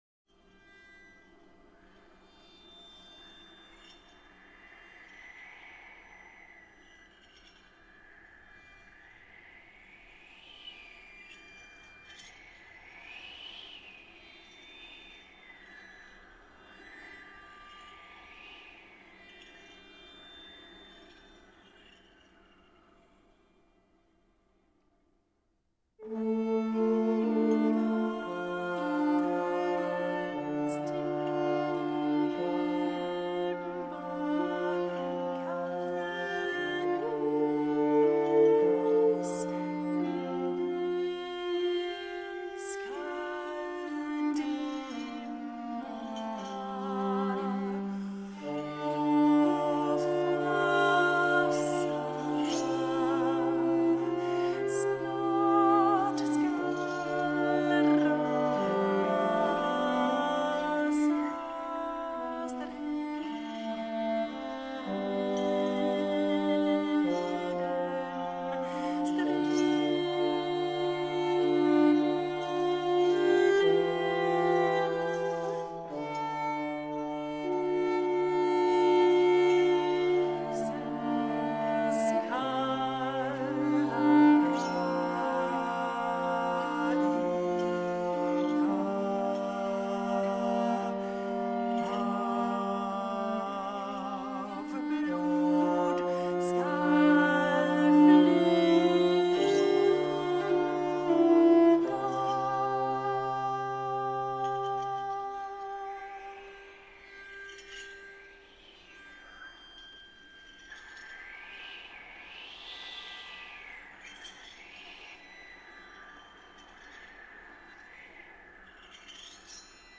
Canon